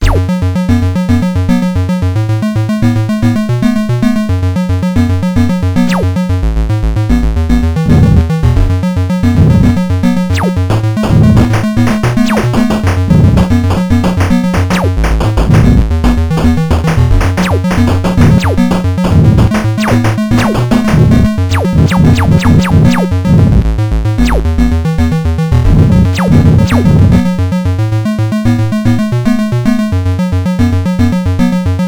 y'a le bruit du laser et l'explosion qui sont des samples, le reste, c'est ABCmusic...